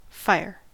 Ääntäminen
US : IPA : [ˈfaɪ.ɚ]